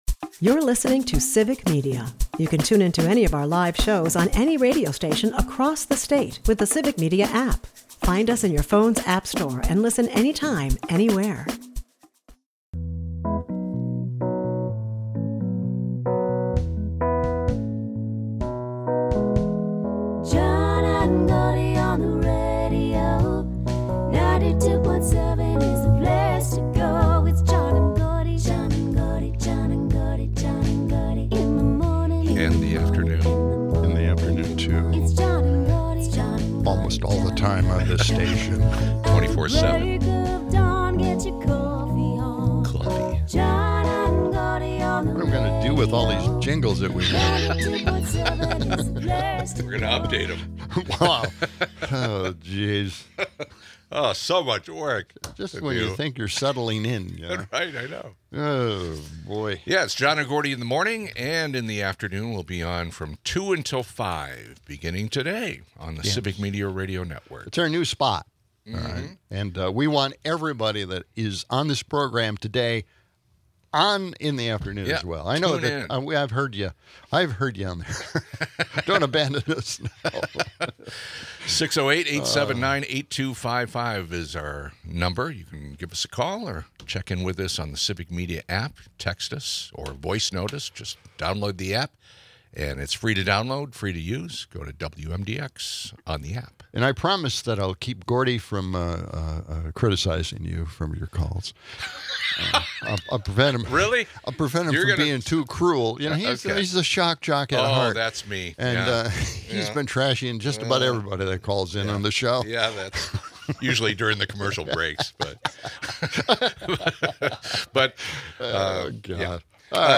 Former Madison Mayor Paul Soglin joins to discuss the Wisconsin governor's race, emphasizing the need for candidates to connect with disenfranchised voters and outline decisive policies on healthcare and minimum wage. Heated calls from listeners tackle claims of missing immigrant children and criticize the Biden administration. There's banter about weather predictions, with a potential polar vortex looming, and a humorous struggle with technical difficulties over sound cuts.